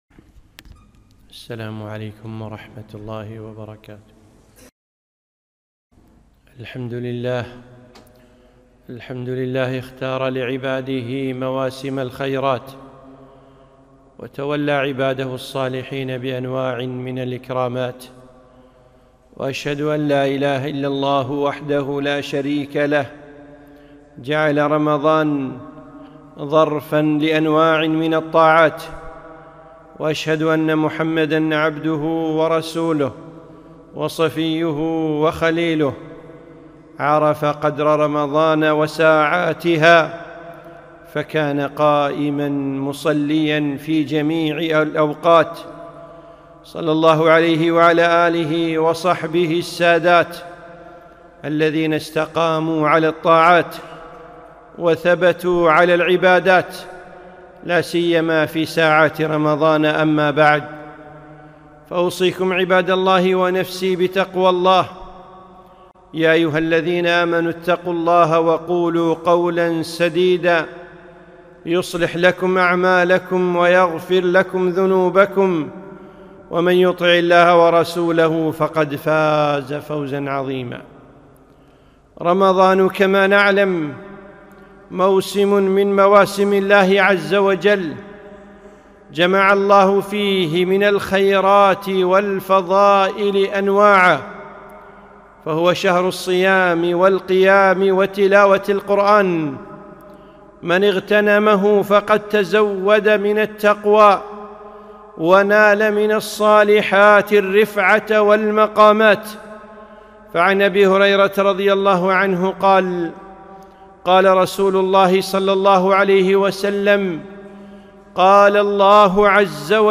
خطبة - ساعات رمضان